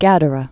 (gădə-rēn, gădə-rēn)